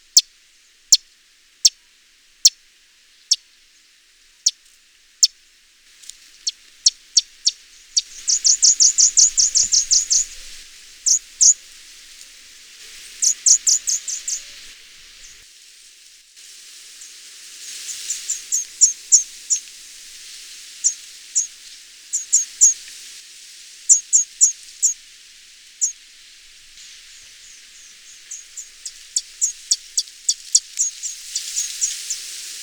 Orange Bishop
Euplectes franciscanus